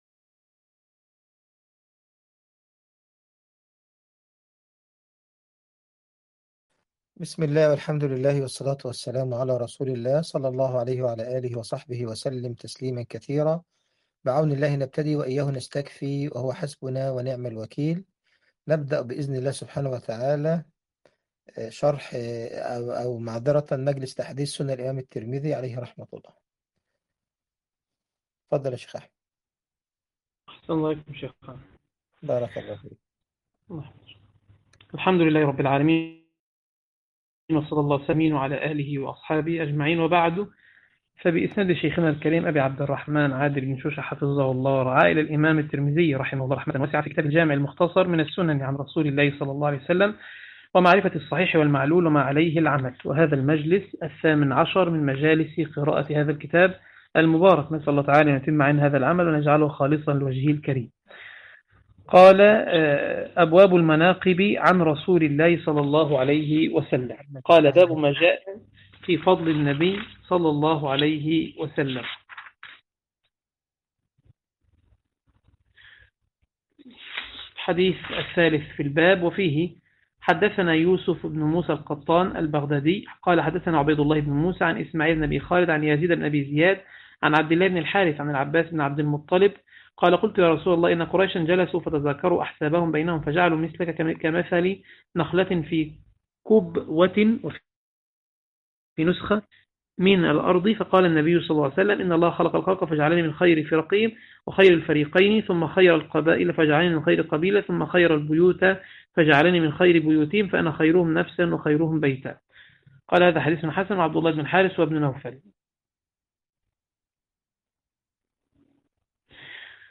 عنوان المادة المجلس 18 - قراءة سنن الإمام الترمذي تاريخ التحميل الثلاثاء 12 اغسطس 2025 مـ حجم المادة 35.24 ميجا بايت عدد الزيارات 61 زيارة عدد مرات الحفظ 76 مرة إستماع المادة حفظ المادة اضف تعليقك أرسل لصديق